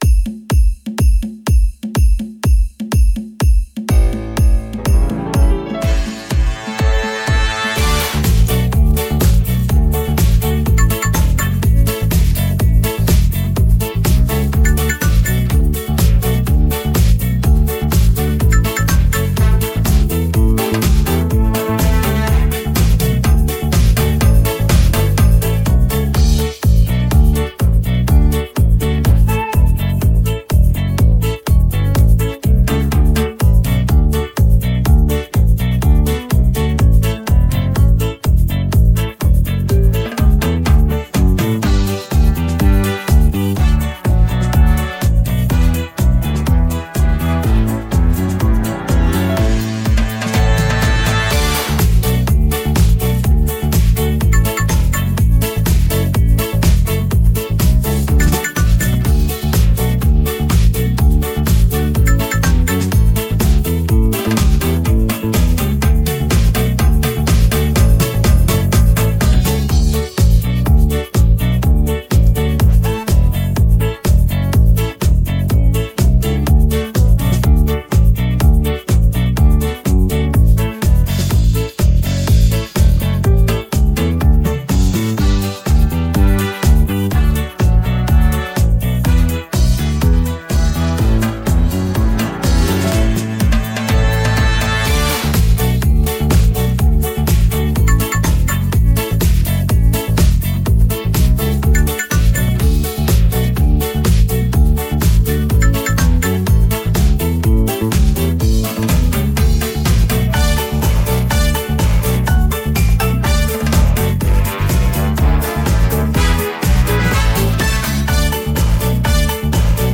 • Категория: Детские песни
Скачать минус детской песни
минусовка